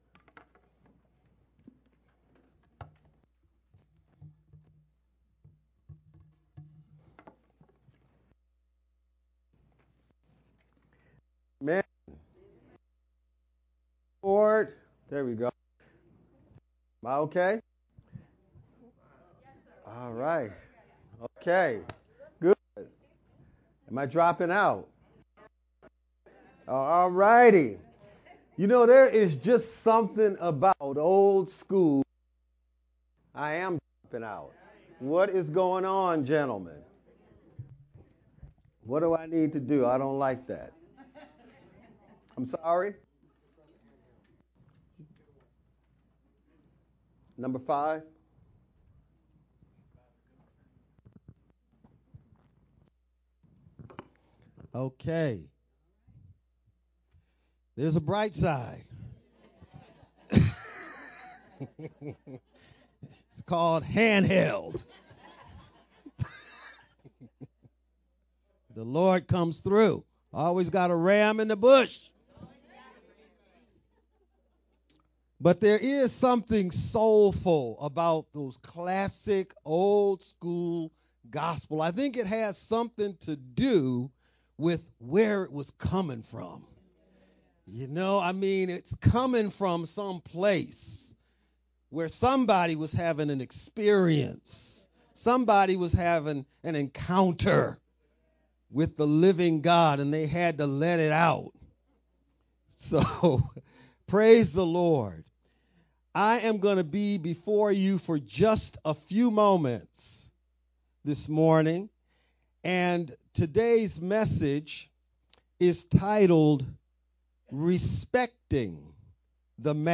VBCC-Sermon-only-edited-2-25_Converted-CD.mp3